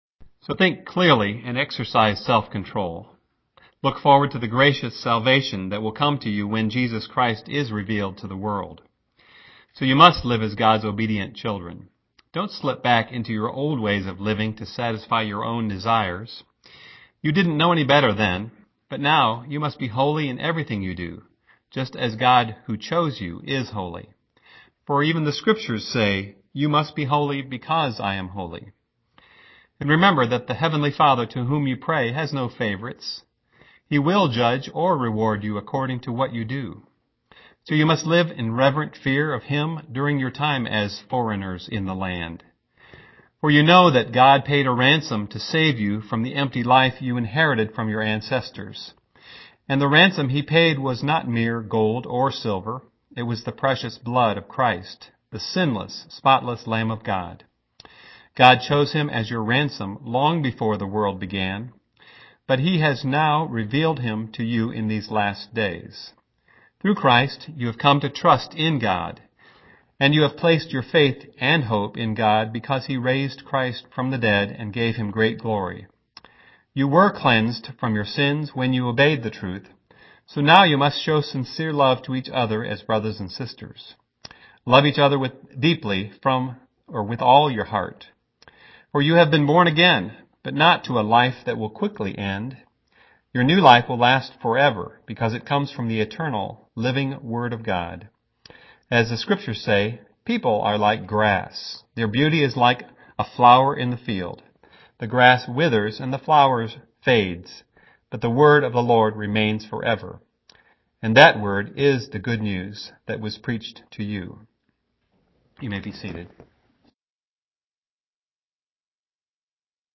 Categories: 1 Peter, 1st Peter: The Church in the World, 2011, Sermons